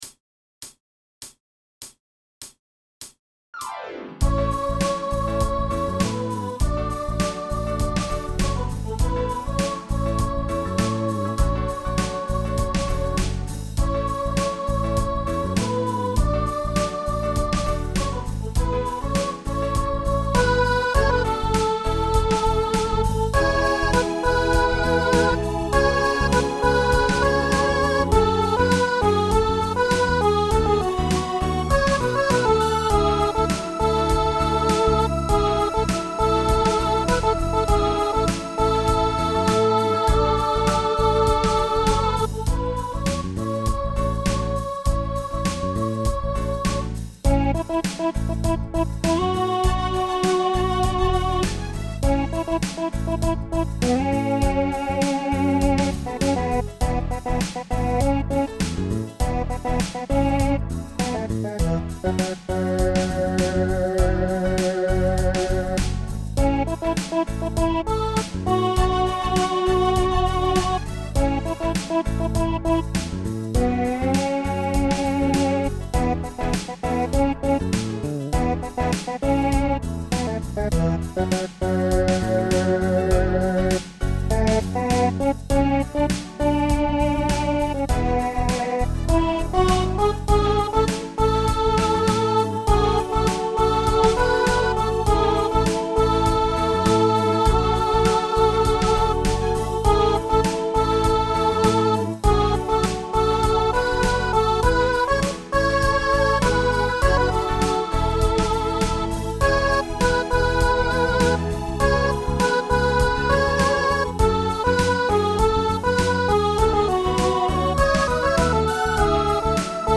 Music / Pop
snes 16-bit 16_bit